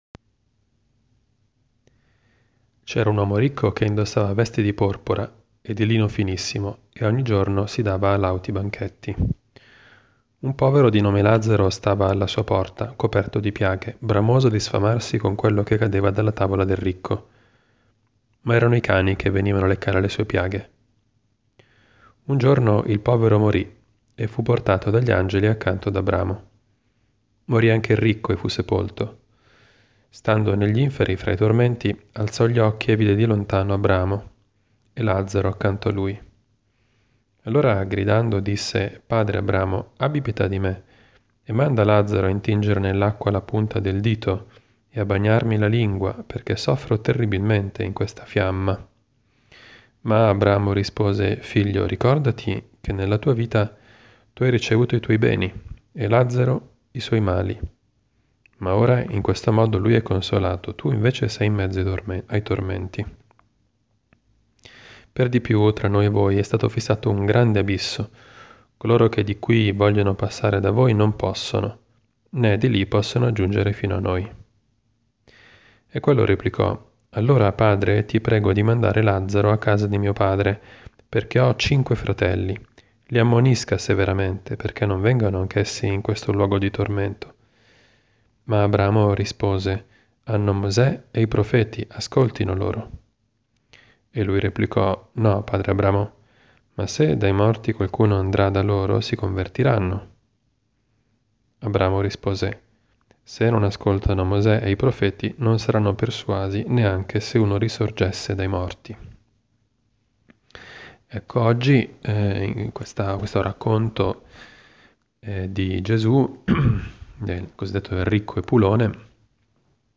Commento al vangelo (Lc 16,19-31) del 1 marzo 2018, giovedì della II settimana di Quaresima.